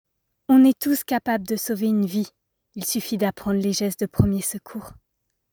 Voix 20-35 ans mère employée boss